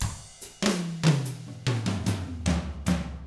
146BOSSAF3-R.wav